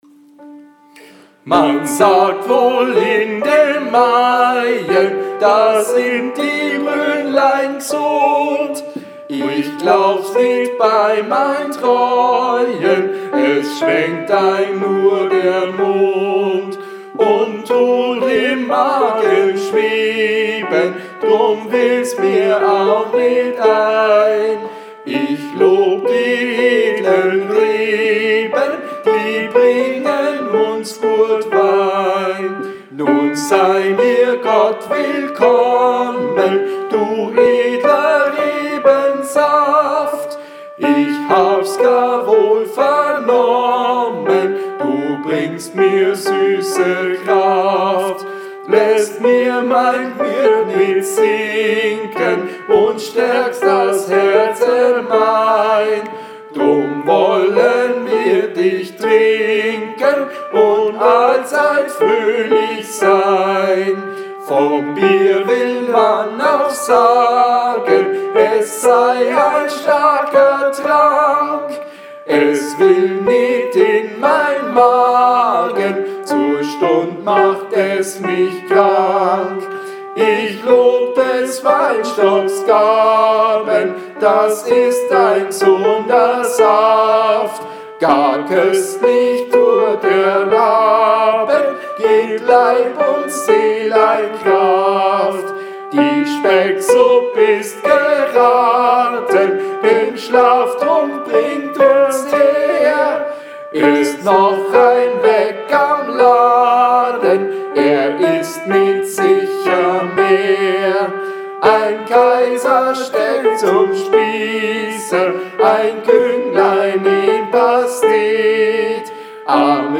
Tenor
06 Edler Rebensaft TENOR.mp3